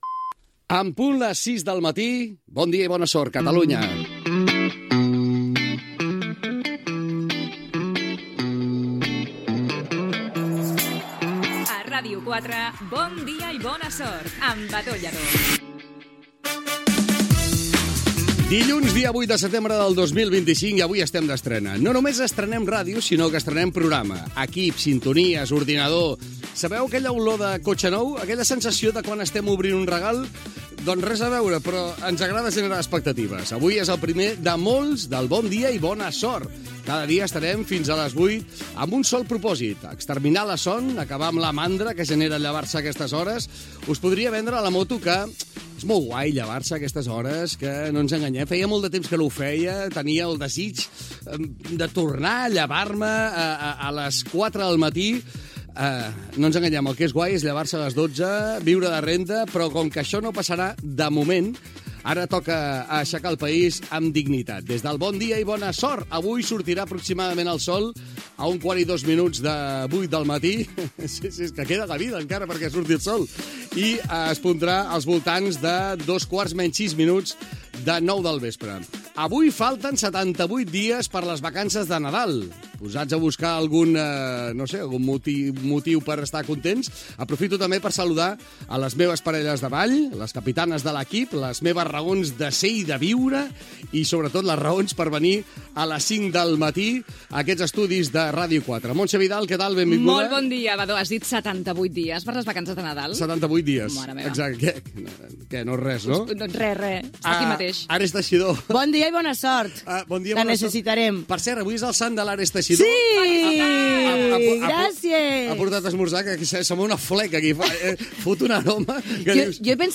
Primera edició del programa despertador, en començar la temporada 2025-2026.
Entreteniment